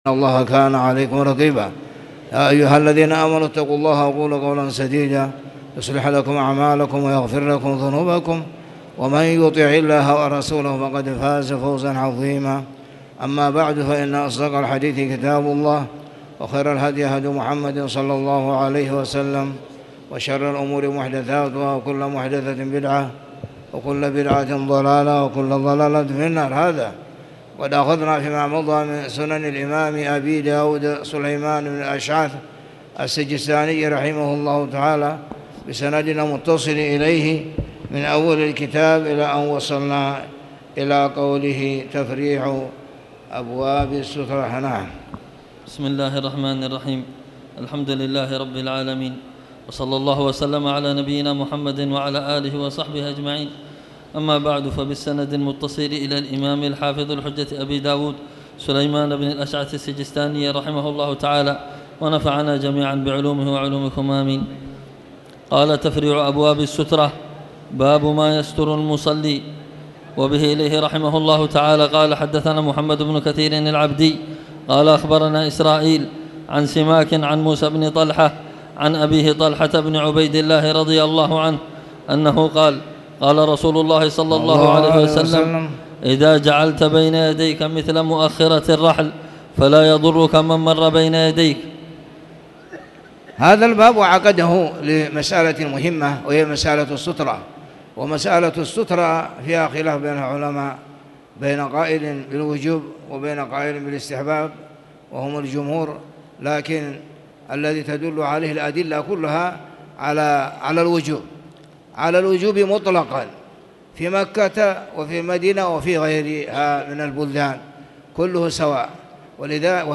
تاريخ النشر ٢٤ محرم ١٤٣٨ هـ المكان: المسجد الحرام الشيخ